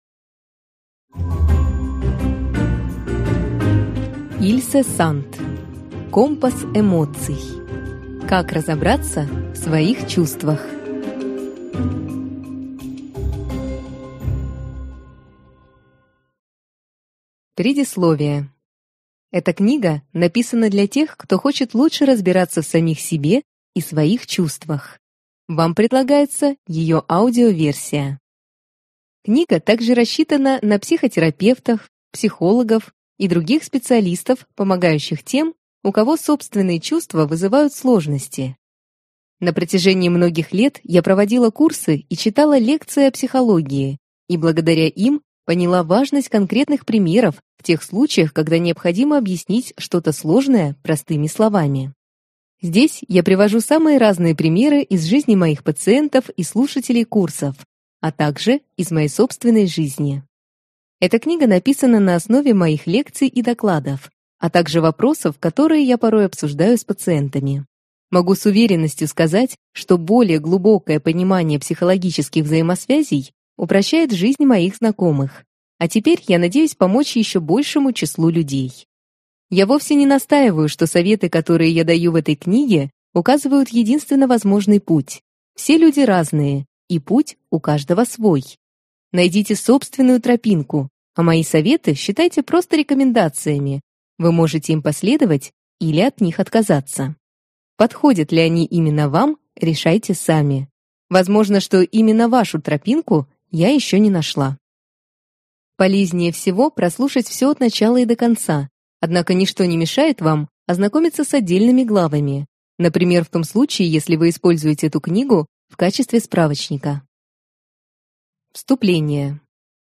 Аудиокнига Компас эмоций: Как разобраться в своих чувствах | Библиотека аудиокниг